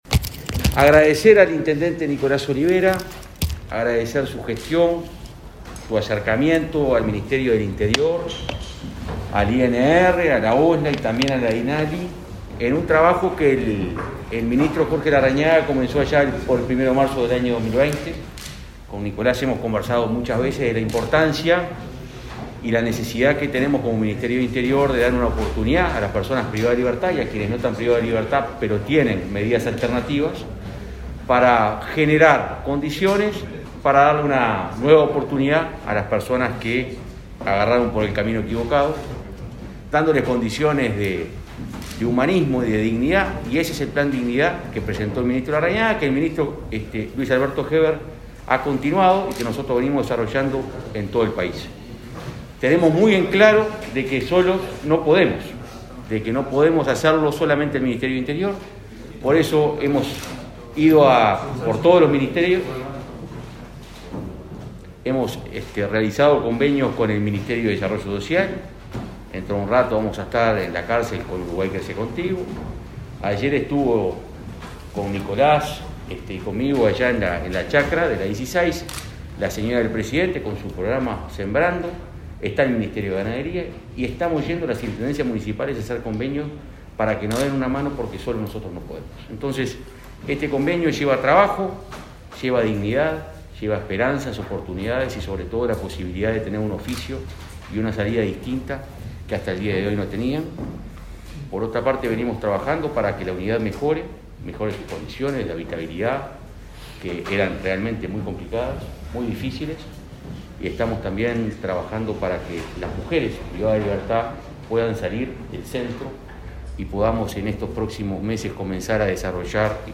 Palabras del director de Convivencia, Santiago González, y del director del INR, Luis Mendoza
Palabras del director de Convivencia, Santiago González, y del director del INR, Luis Mendoza 20/08/2021 Compartir Facebook X Copiar enlace WhatsApp LinkedIn Este viernes 20, el director de Convivencia del Ministerio del Interior, Santiago González, y el director del Instituto Nacional de Rehabilitación (INR), Luis Mendoza, participaron de la firma de un convenio entre esa secretaría de Estado y la Intendencia de Paysandú.